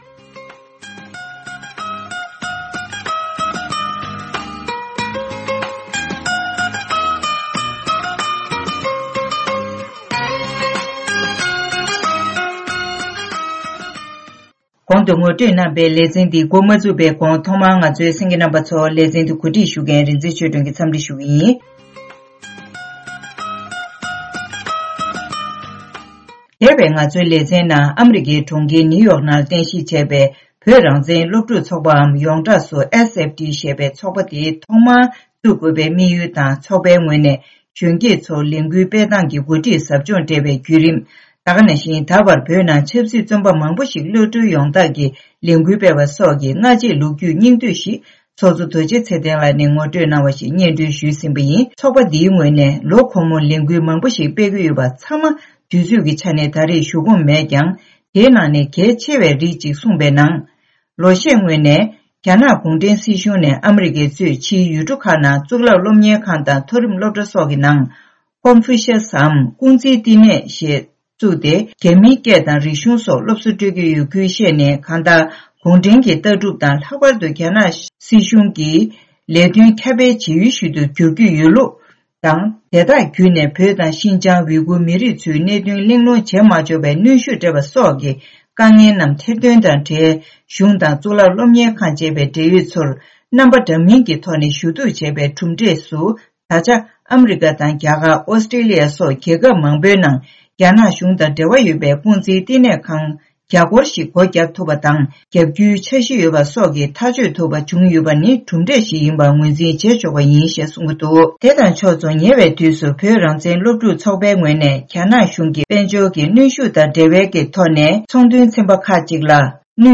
བཀའ་འདྲི་ཞུས་ཏེ་ཕྱོགས་བསྒྲིགས་ཞུས་པ་ཞིག